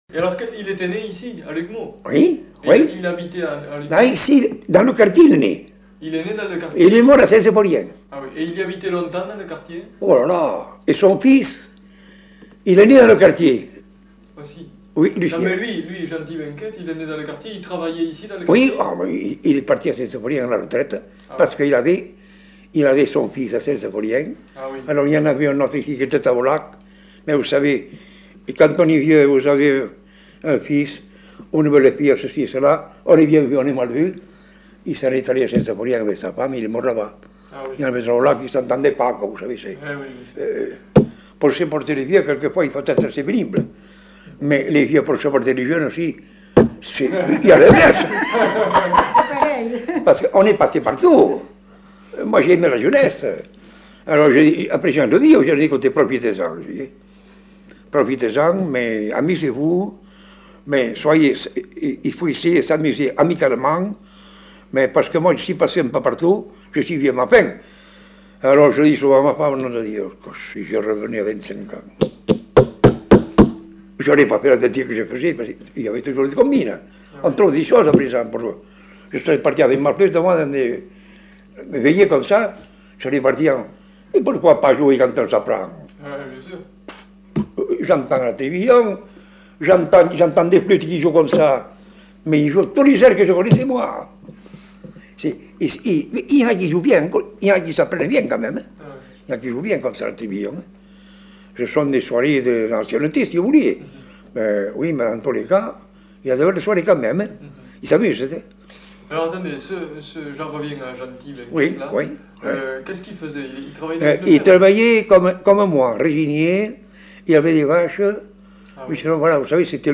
Aire culturelle : Bazadais
Genre : témoignage thématique
Ecouter-voir : archives sonores en ligne